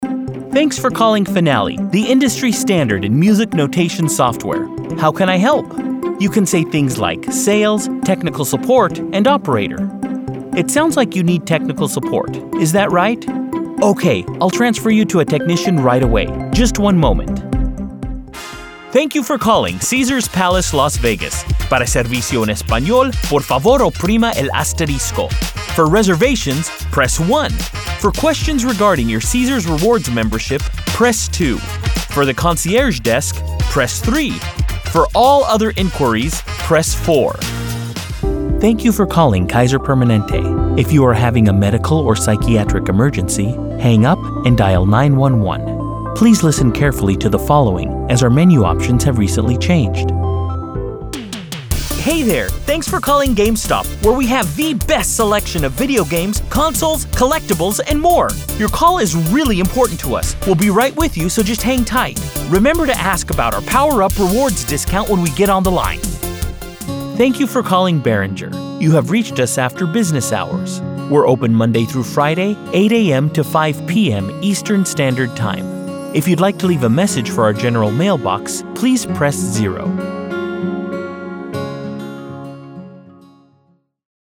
Phone Systems/IVR demo
• Friendly guy-next-door;
• Caring, empathetic;
• Professional, business, corporate; instructor;